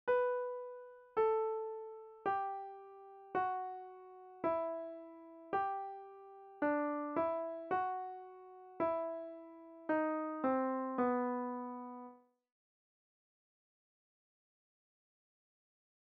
This tune is wonderfully "modal" - that is, neither major nor minor.
In the same way, "and" in the second line is the same pitch as the first note of "body", and then drops a fourth.
Thanksgiving_Hymn_A_trouble_phrase.mp3